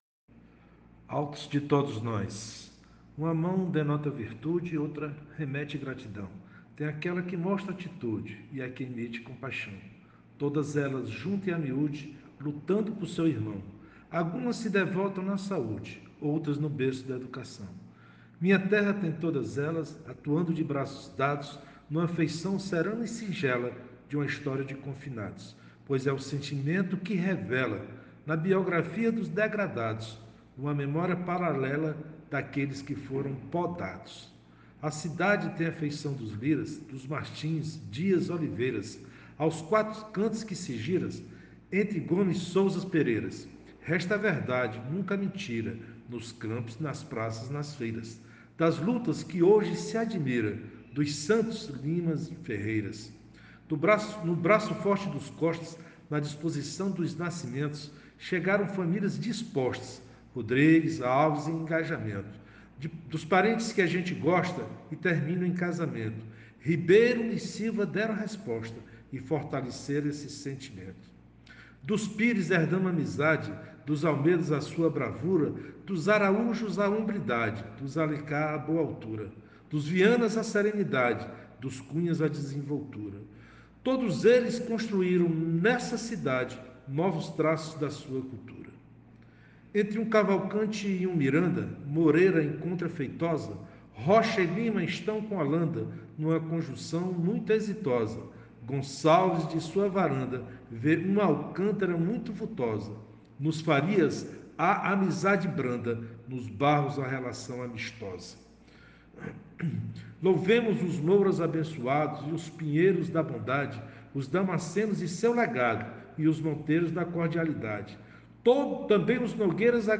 01:35:00   Verso Recitado